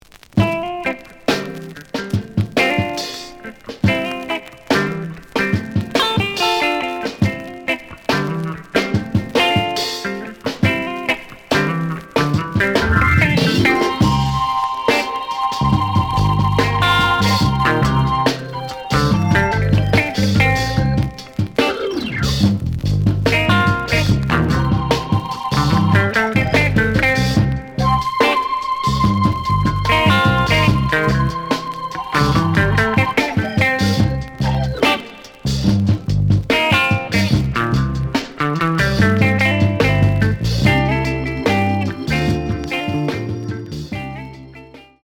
The audio sample is recorded from the actual item.
●Genre: Funk, 60's Funk
Some noise on both sides.